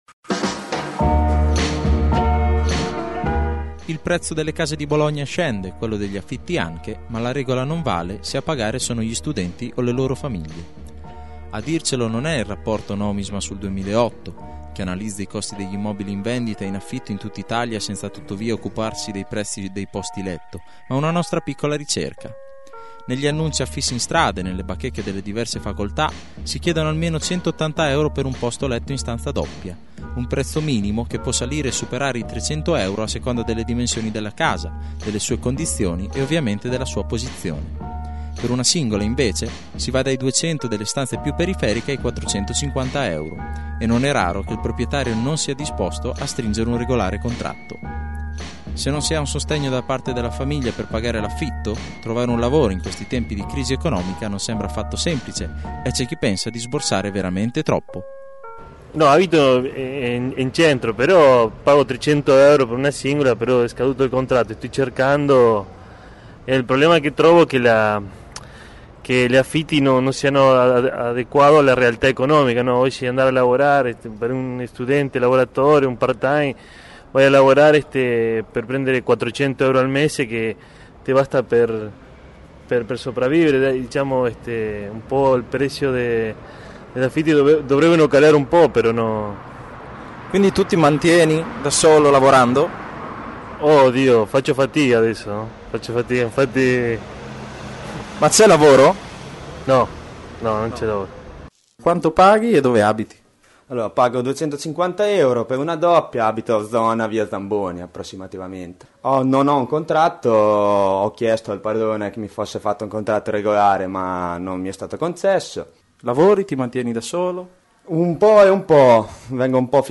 La “caccia” alla nuova sistemazione si snoda fra volantini affissi in strada e nelle bacheche delle facoltà, ma non solo. Ci siamo stati anche noi, per scoprire l’andamento dei prezzi e incontrare i ragazzi che cercano una nuova casa o un nuovo coinquilino.